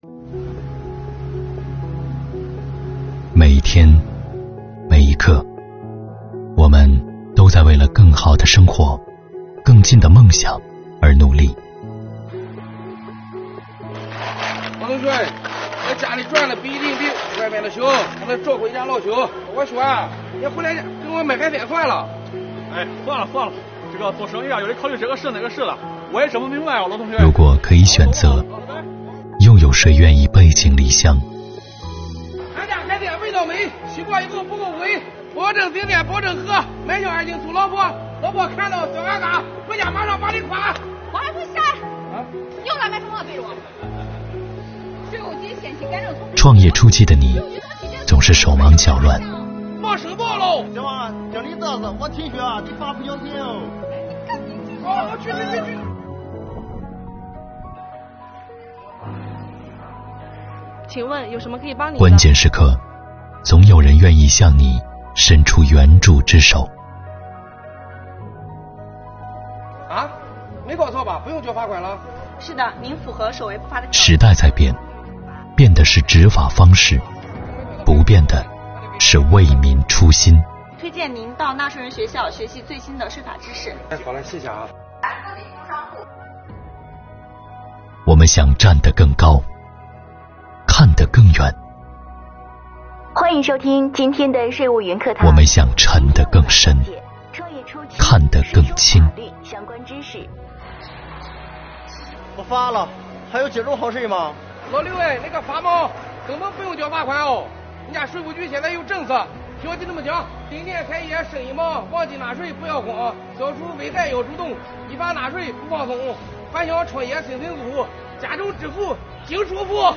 作品采用剧情与旁白结合的方式，幽默与温情并重，生活气息浓郁，方言的讲述更是拉近与观众的距离，让观众在轻松愉快的氛围中感受法润民生的底蕴。